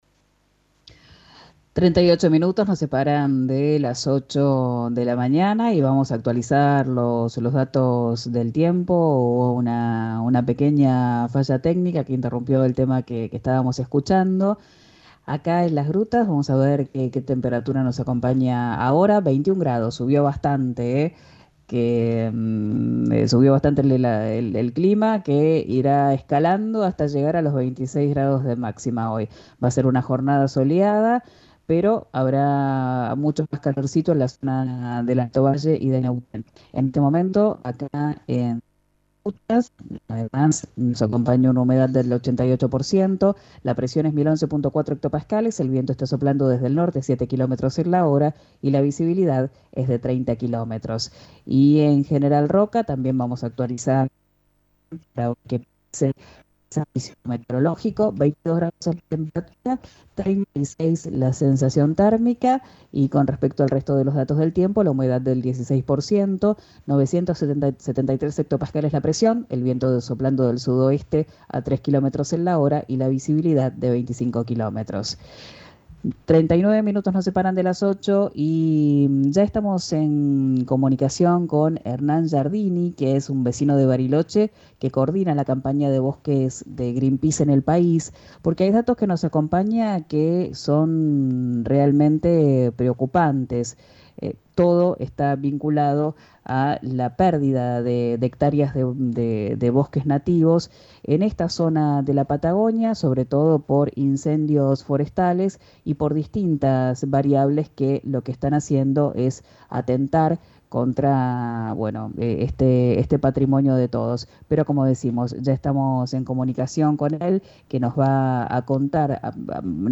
Escuchá la entrevista en 'Quien dijo verano', por RÍO NEGRO RADIO.